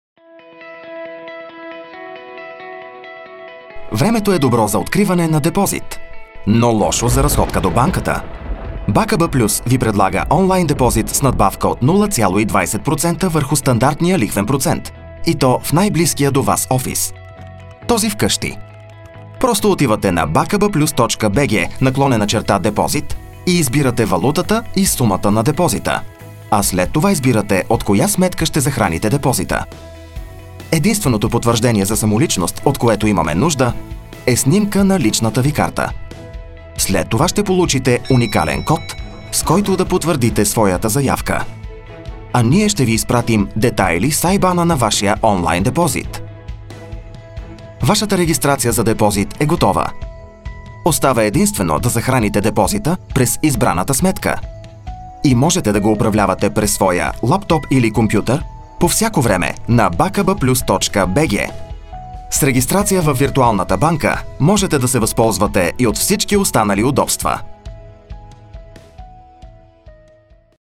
Commerciale, Naturelle, Cool, Chaude, Corporative
Vidéo explicative